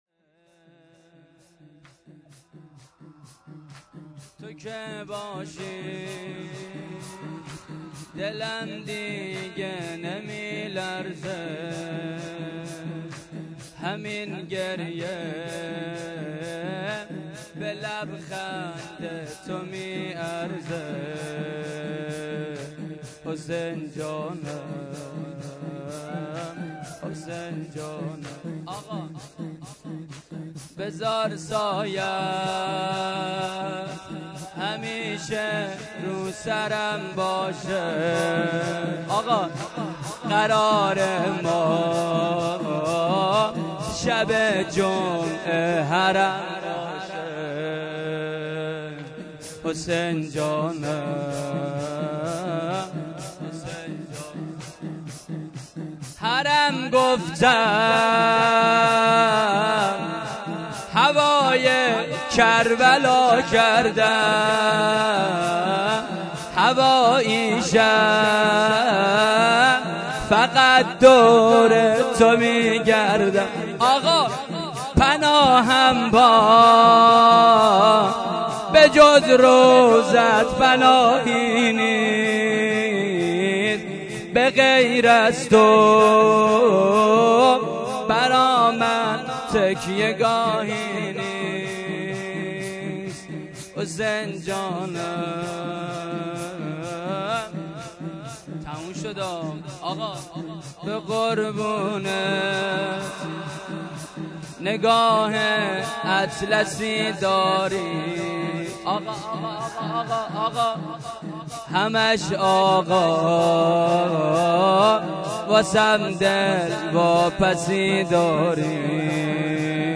چهار ضرب
شب‌ نهم محرم الحرام ۹۷ هیات انصار المهدی (عج)